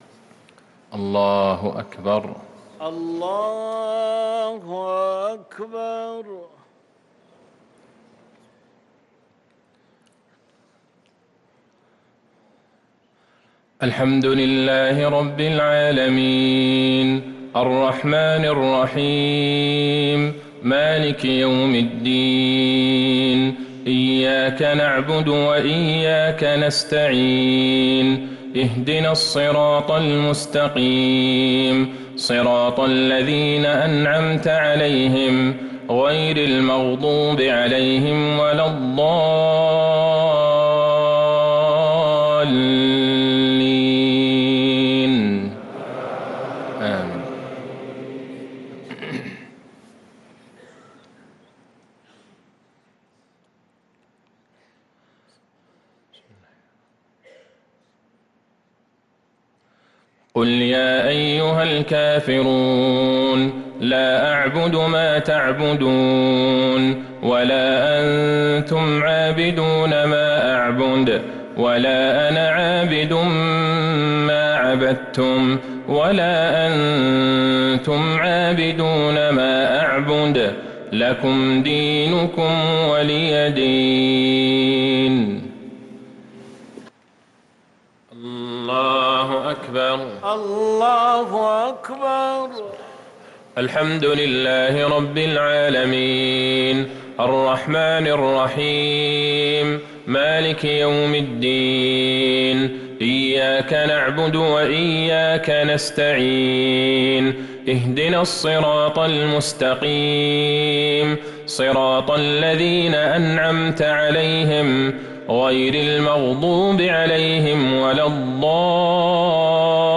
صلاة المغرب للقارئ عبدالله البعيجان 24 محرم 1445 هـ